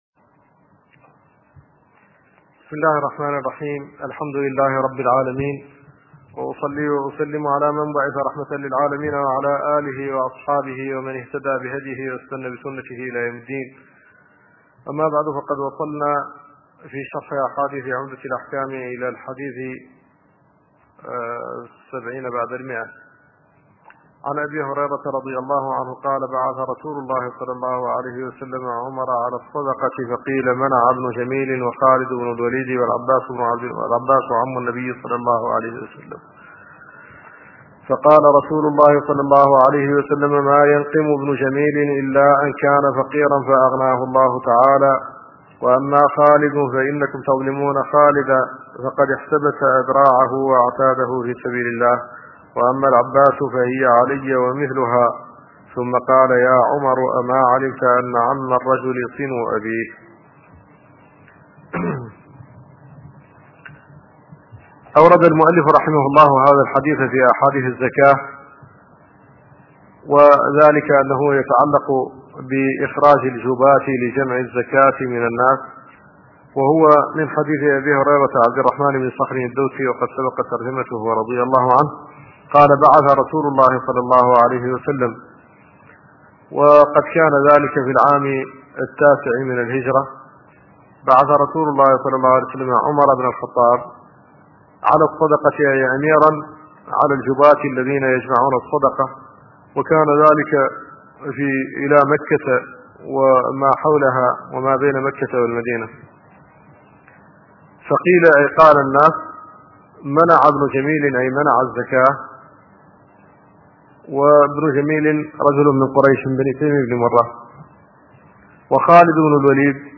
أرشيف الإسلام - ~ أرشيف صوتي لدروس وخطب ومحاضرات الشيخ محمد الحسن الددو الشنقيطي